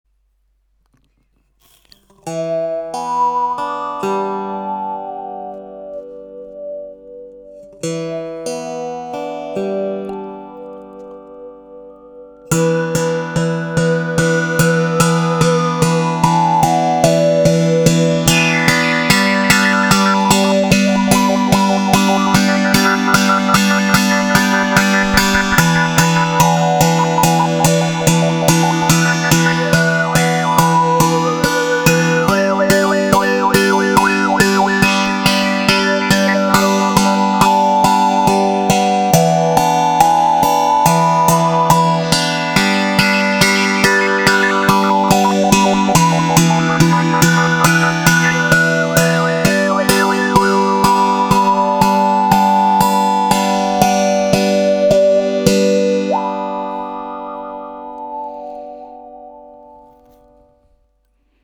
Le son est très chaud et attrayant…entrainant.
Les sons proposés ici sont réalisés sans effet.
La baguette sera utilisée pour percuter les cordes, l’une, l’autre ou toutes.